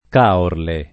[ k # orle ]